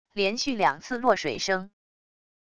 连续两次落水声wav音频